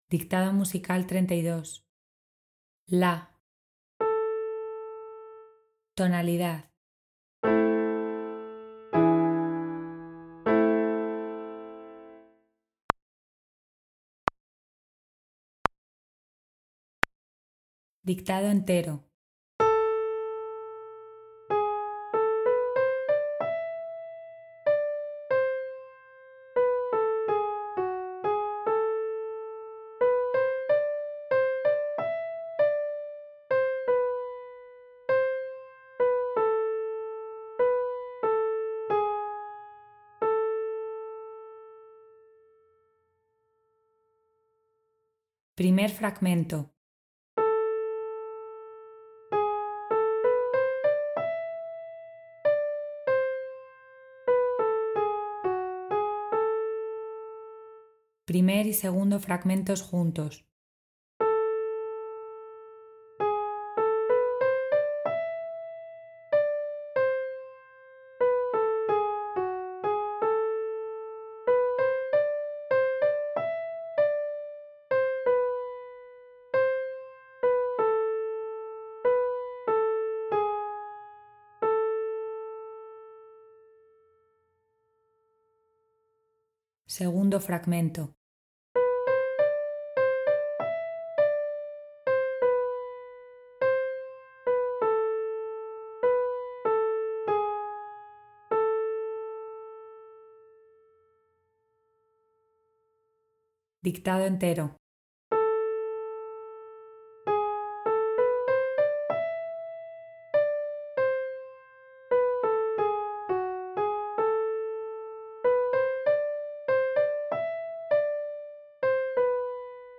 A continuación vas a encontrar un ejercicio de dictado musical en PDF para que te lo descargues y puedas resolverlo con su audio correspondiente.
Primero escucharás la nota La como sonido de referencia, posteriormente se tocarán los acordes de la tonalidad en la que nos encontramos y el dictado entero. El ejercicio se dictará por fragmentos y al finalizar escucharemos de nuevo el dictado entero para comprobar el resultado final de nuestra escritura.